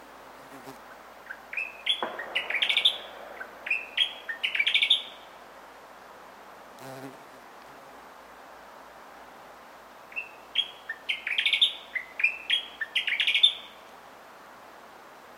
Пение таежного сверчка, 01.07.2022.
Пение таёжного сверчка, 01.07.2022., в окр. Чистых Ключей